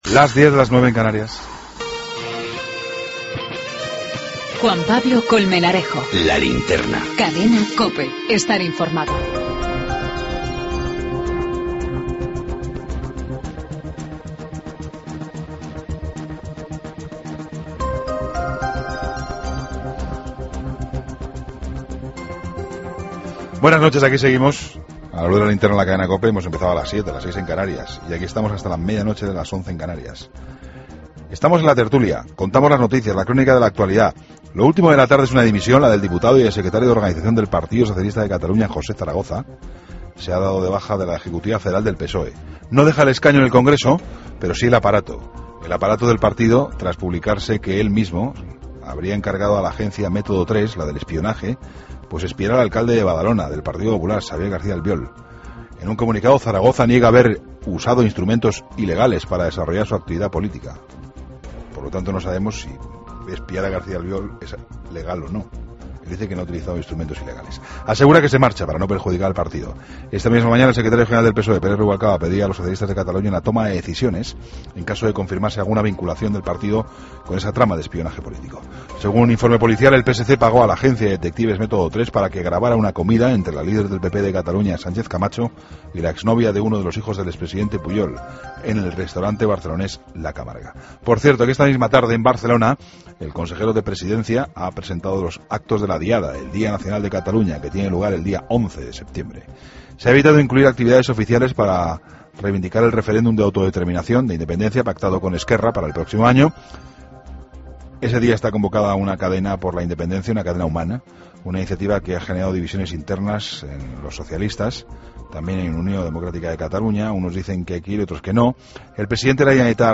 Entrevista a José Luis Sanz, secretario general del PP en Andalucía